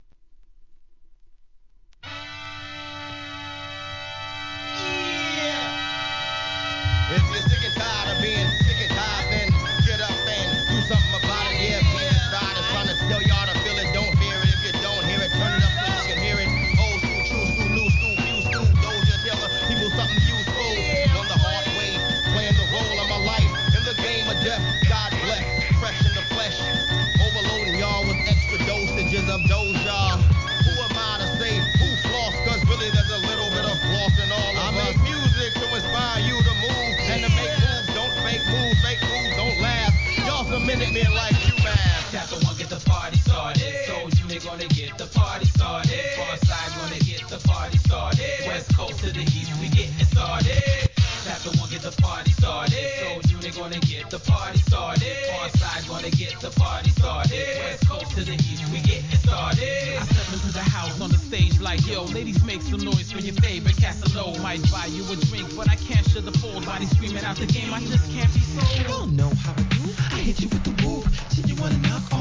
HIP HOP/R&B
サマー・アンセム！